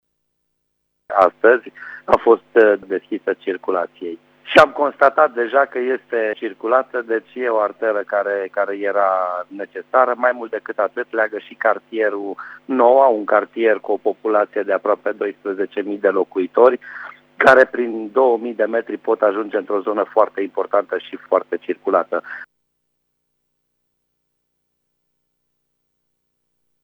Traficul se desfășoară din plin încă de la momentul inaugurării șoselei, a spus administratorul public al municipiului Brașov, Miklos Ganz: